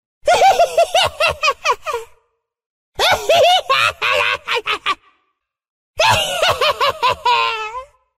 Risada Veigar (LoL)
Risada do personagem Veigar do game League Of Legends (LoL), também conhecido como o Pequeno Mestre do Mal.
risada-veigar-lol.mp3